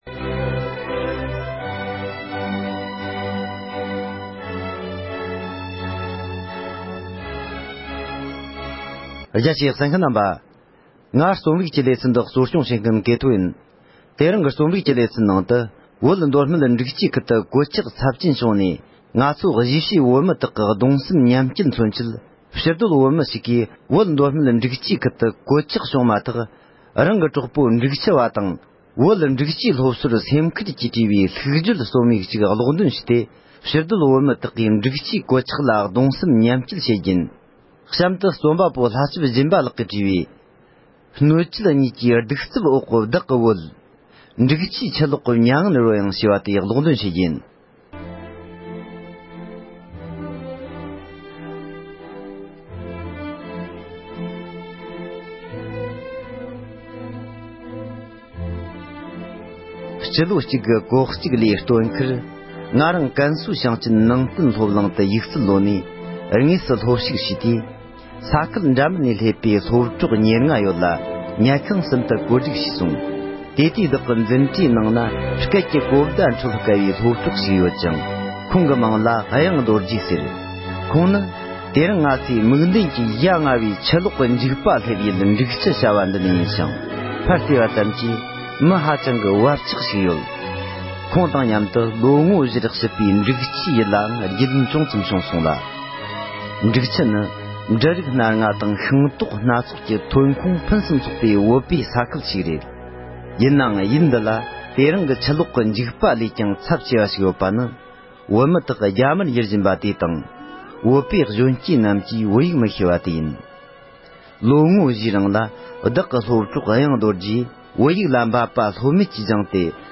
བོད་མདོ་སྨད་འབྲུག་ཆུའི་གོད་ཆགས་ལ་ཕུལ་བའི་ལྷུག་བརྗོད་རྩོམ་ཡིག་གཅིག་དང་མྱ་ངན་གྱི་སྙན་ངག་ཅིག་ཀློག་འདོན་ཞུས་པ།